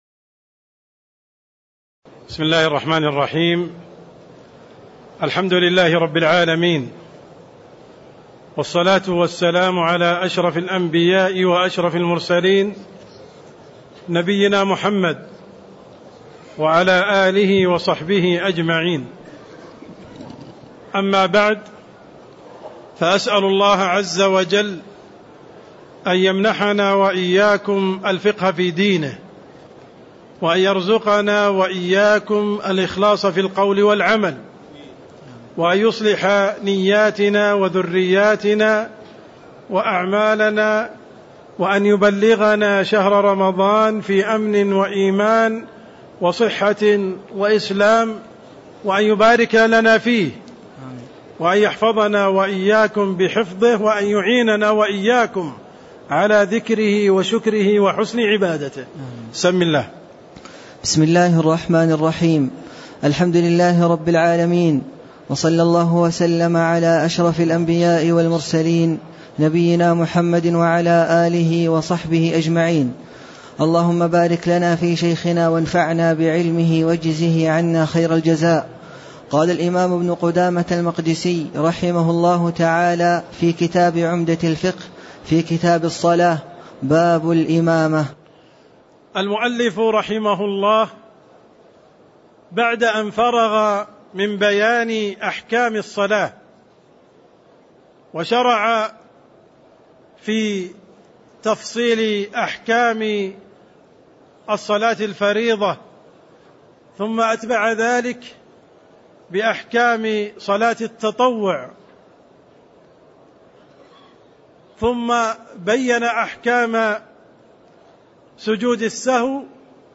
تاريخ النشر ٩ شعبان ١٤٣٦ هـ المكان: المسجد النبوي الشيخ: عبدالرحمن السند عبدالرحمن السند باب الإمامة (14)